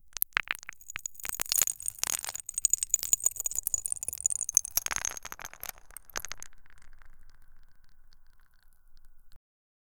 Crystals falling on the ground 0:05 Sharp crystalline stones cracking and breaking apart, high-pitched, delicate yet impactful fractures, small shards scattering, crisp and sparkling textures, echo of thin crystal splitting, detailed micro-fractures, clean and bright sound 0:10 the sound of a magic crystal breaking 0:02
sharp-crystalline-stones--elsatbgr.wav